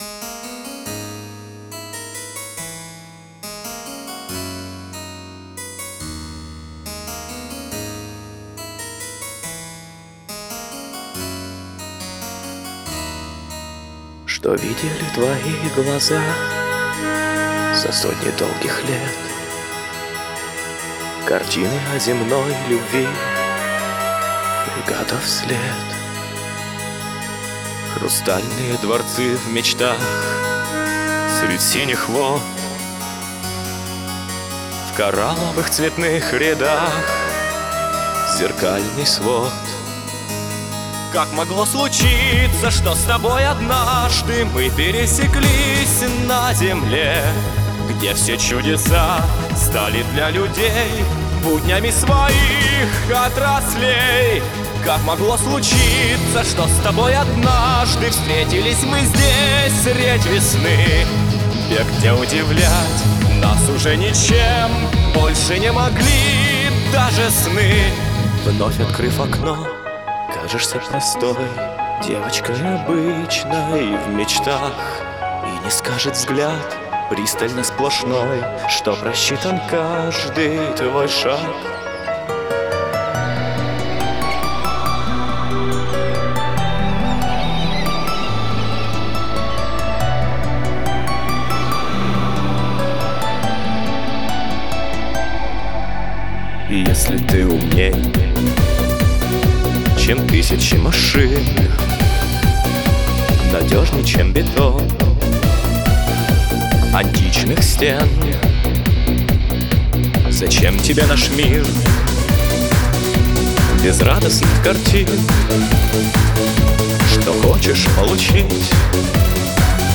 Техно-опера